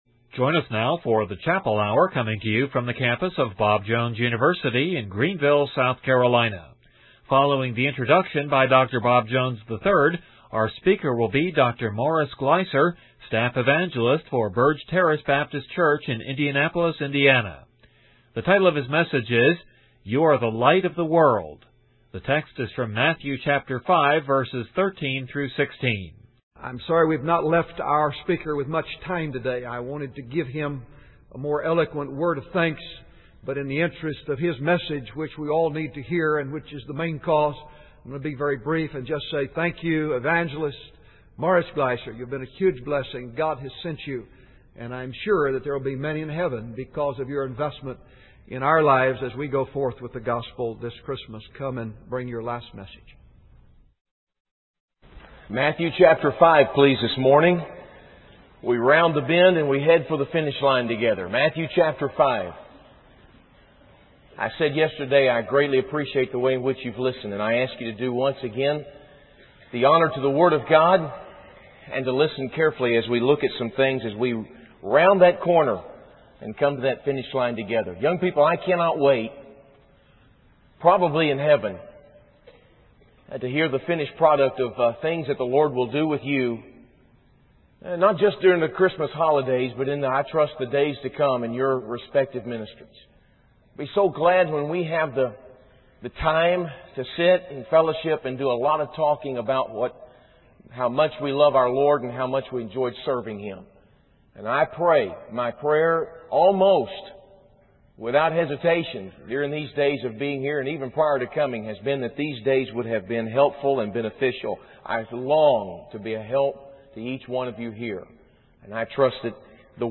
In this sermon, the preacher emphasizes the darkness and brokenness of the world we live in, citing examples such as crime, news reports, and school shootings. He then highlights the message of Jesus, who calls believers to be the light of the world. The preacher encourages young people to recognize their purpose and potential in being a light in the midst of darkness.